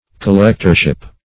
Meaning of collectorship. collectorship synonyms, pronunciation, spelling and more from Free Dictionary.
Search Result for " collectorship" : The Collaborative International Dictionary of English v.0.48: Collectorship \Col*lect"or*ship\, n. The office of a collector of customs or of taxes.
collectorship.mp3